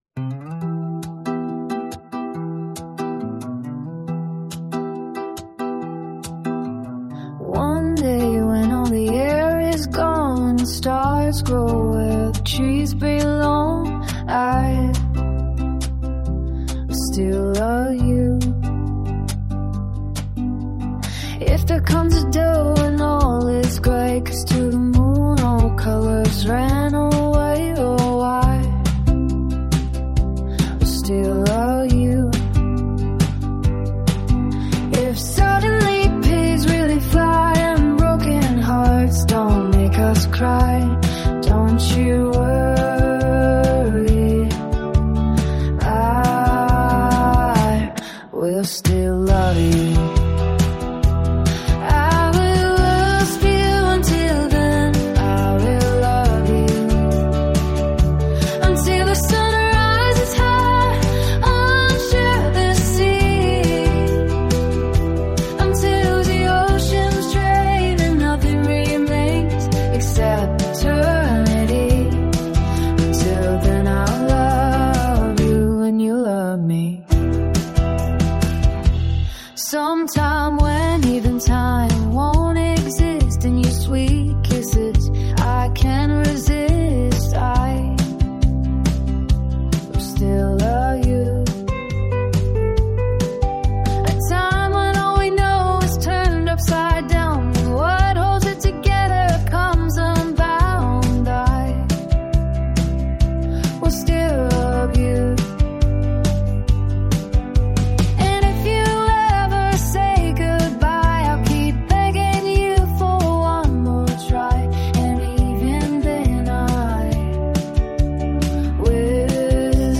It's an app that can make musical demos of your song lyrics.
elegant message and voice...I'm in awe, truly!